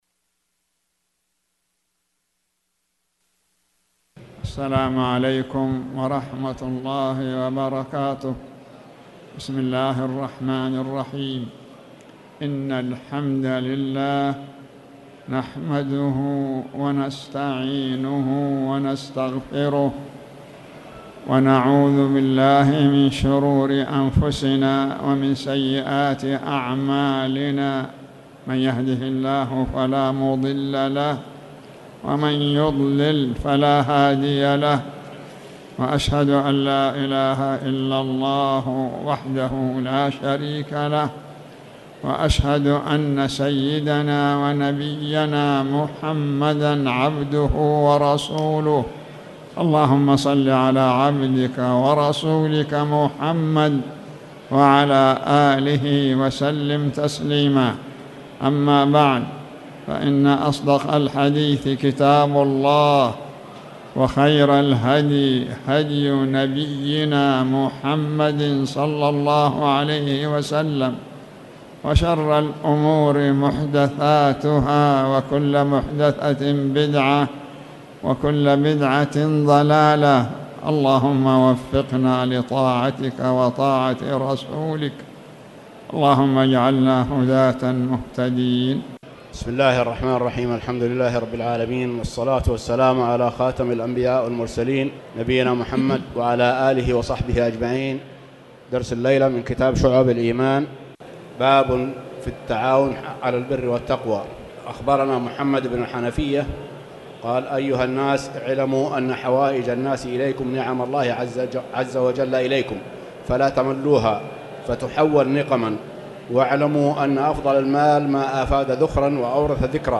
تاريخ النشر ٢٥ صفر ١٤٣٩ هـ المكان: المسجد الحرام الشيخ